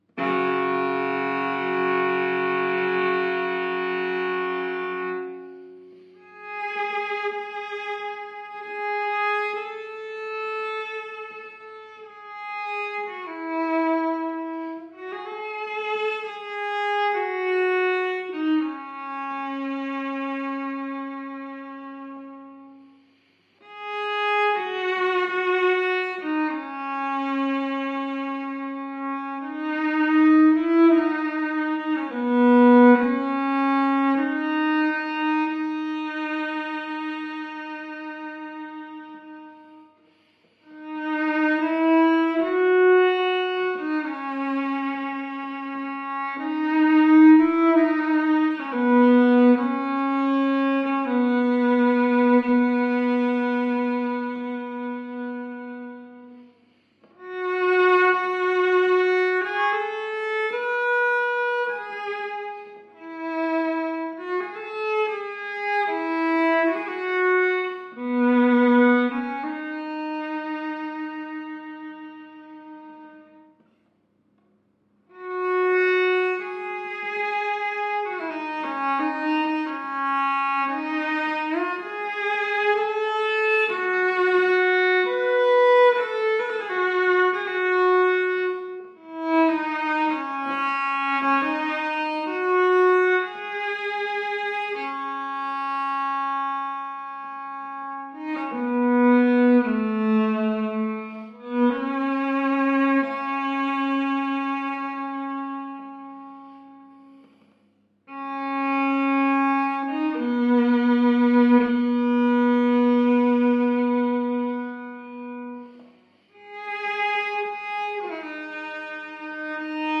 The fianal cadence of this anonymous ‘Preludio’ A 17th Century violinist enjoying themselves